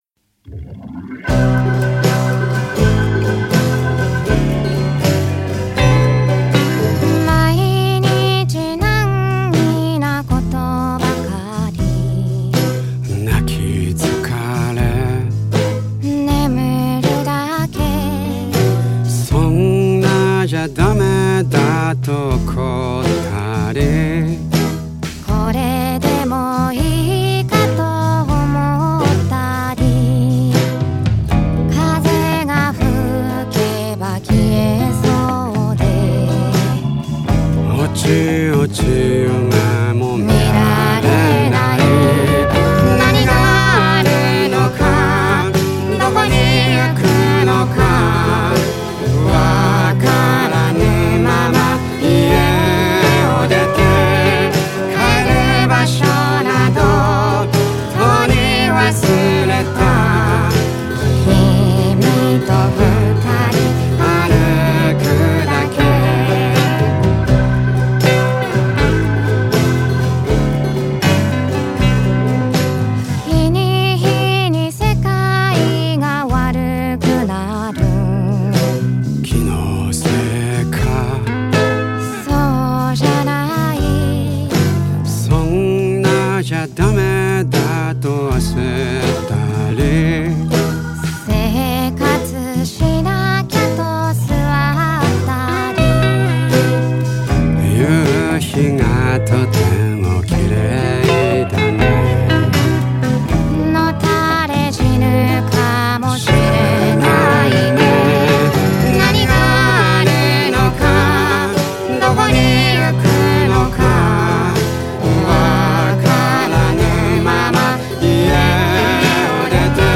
ハヤえもんを使ってキーをＣに変更して、歌詞カード見ながら合わせました。